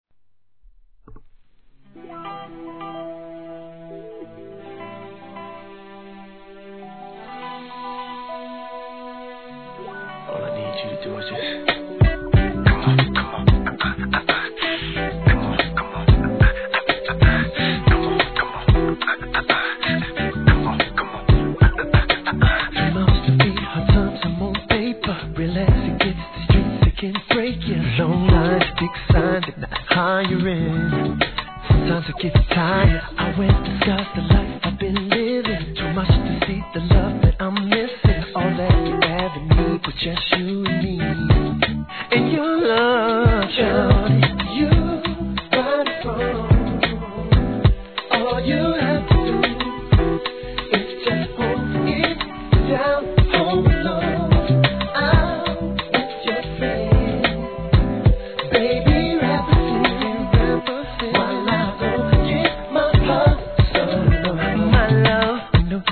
HIP HOP/R&B
彼の実力ある歌唱力は聴けば分かるはずです!!